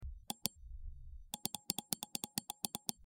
Mouse Click
Mouse_click.mp3